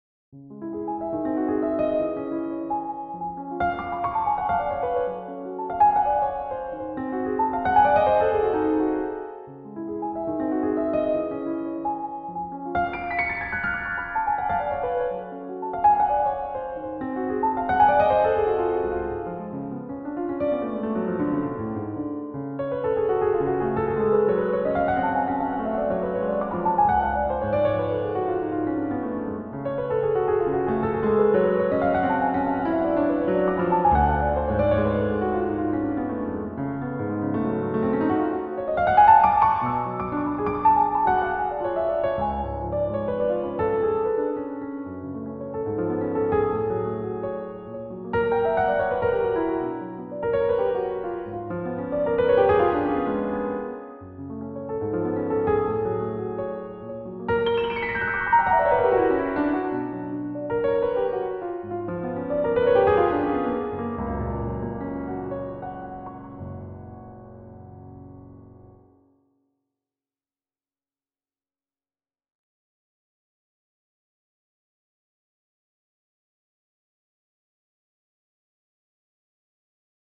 J’utilise un piano numérique.
Vous pouvez appliquer un certain nombre de filtres et en particulier assigner un peu de réverbération qui donne une amplitude « pro » au son.